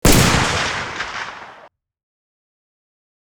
dragonov_shot.wav